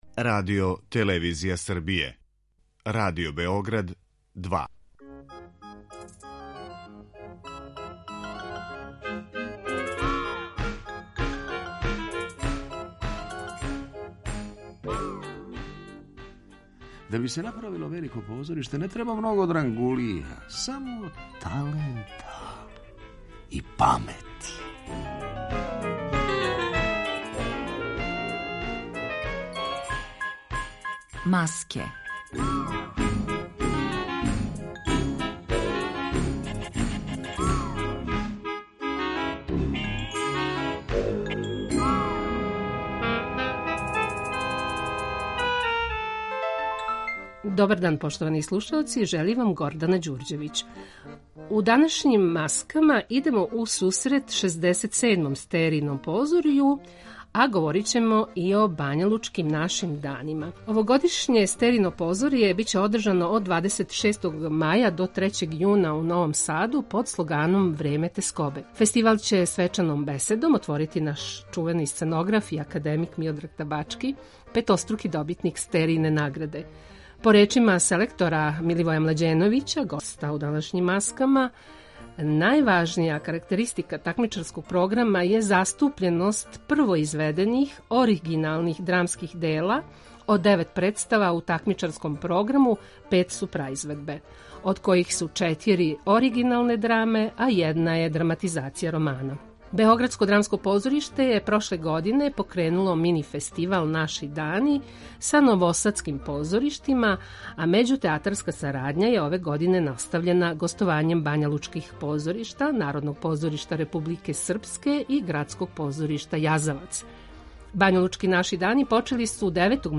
Све епизоде серијала Аудио подкаст Радио Београд 2 Колаж Сленг